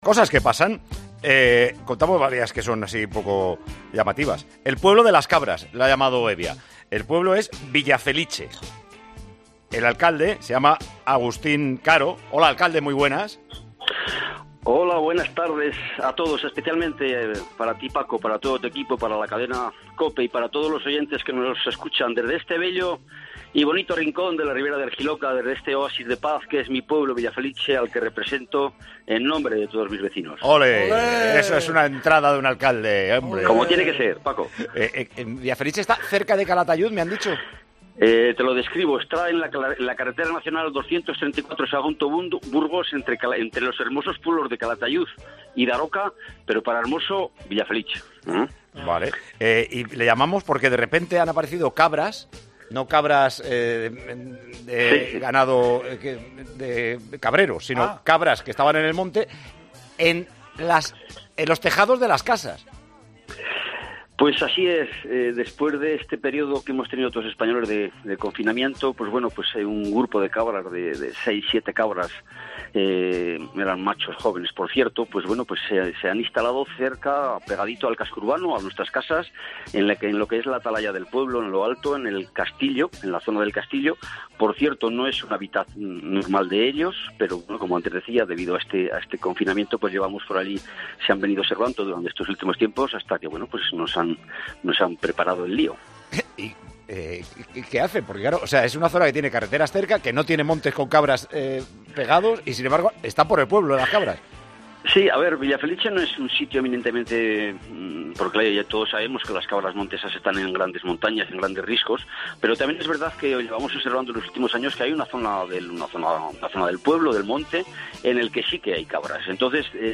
Villafeliche es un pueblo de Zaragoza en el que las cabras han tomado protagonismo en la cuarentena. "Nos encontramos 6 o 7 saltando de tejado en tejado", nos cuenta su alcalde.